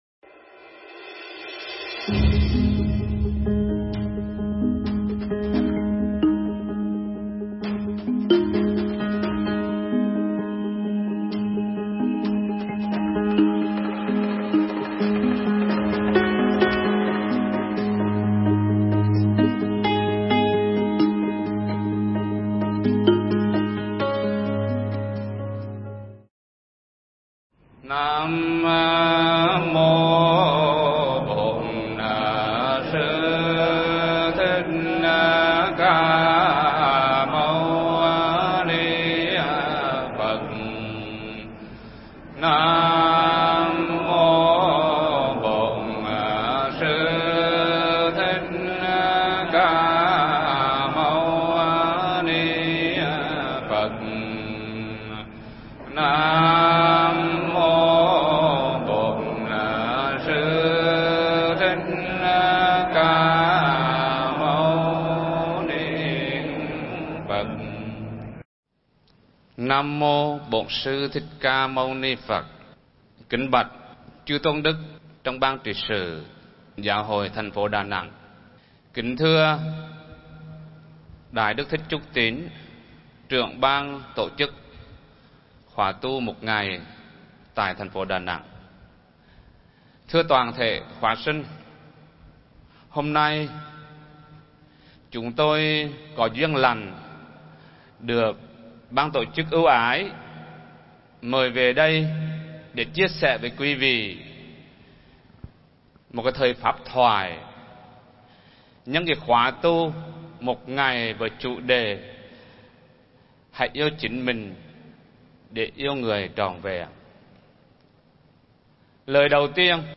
Pháp âm Hãy Biết Yêu Chính Mình Để Yêu Người Trọn Vẹn
giảng tại cung thể thao Tiên Sơn (Đà Nẵng) trong khoá sinh hoạt về tình yêu, hôn nhân và gia đình